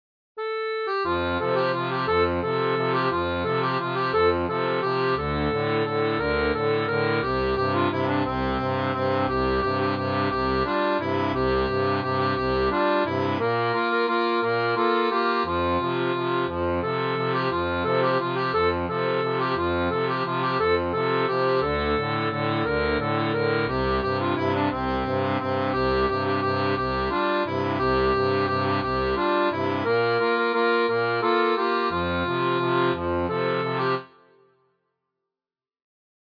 • Une tablature pour diato à 2 rangs transposée en Sol
Folk et Traditionnel